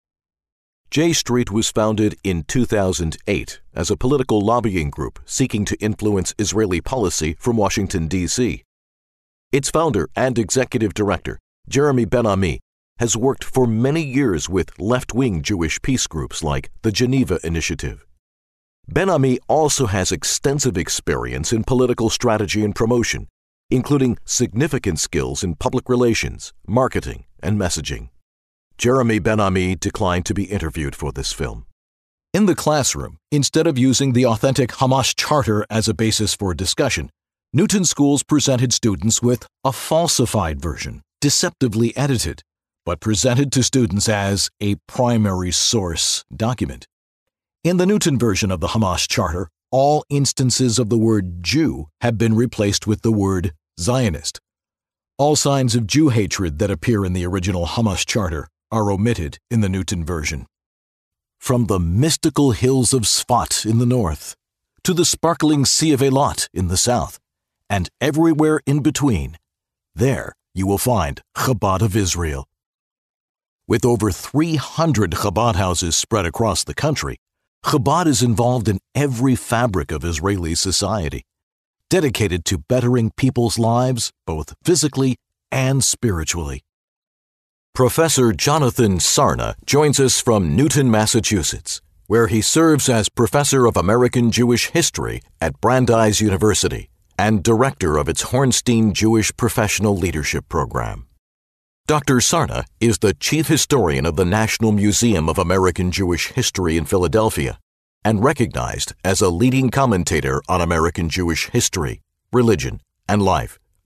Documentary 1